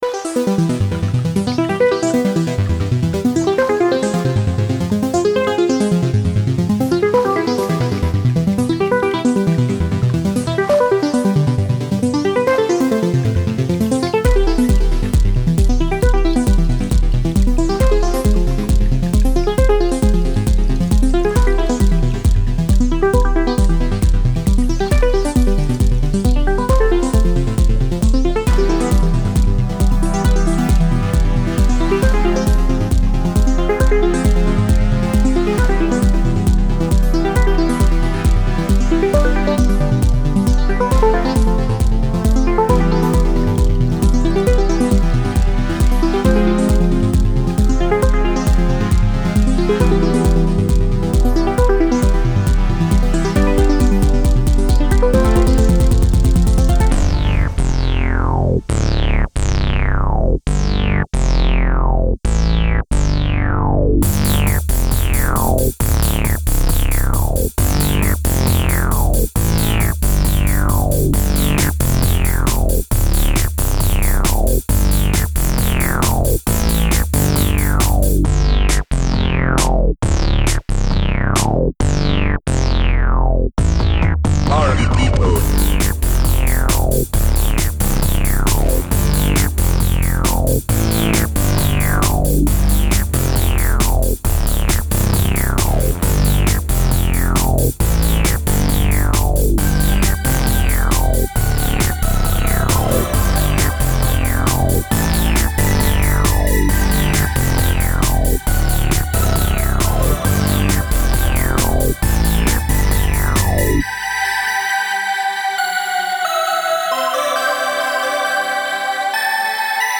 this synth-filled music (yes, it is 6:01) (please complain about everything you can!)
More Synth! (final).mp3